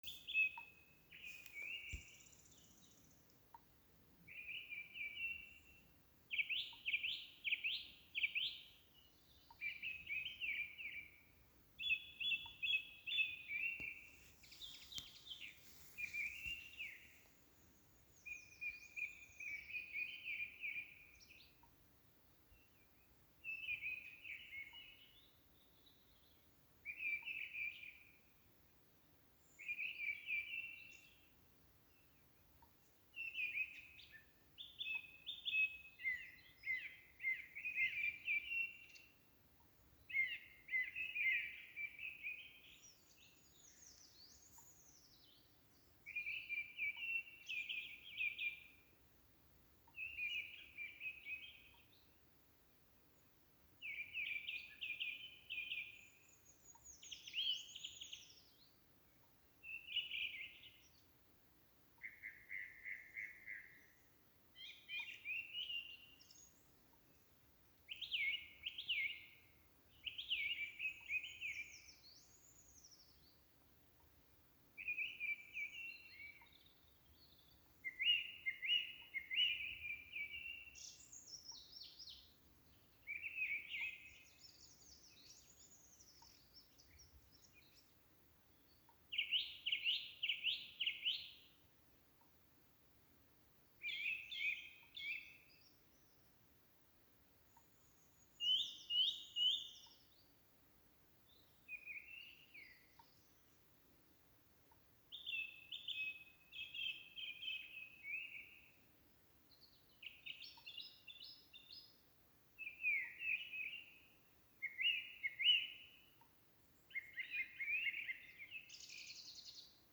Grives-Gue-de-la-chaux.mp3